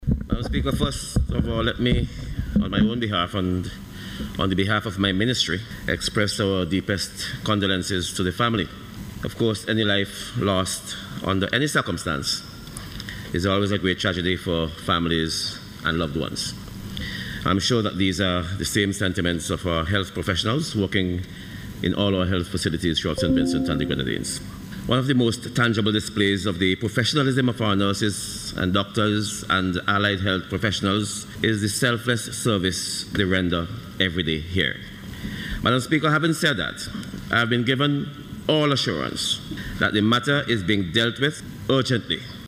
Portfolio Minister, St. Clair Prince addressed the issue in response to a question about the incident in Parliament yesterday.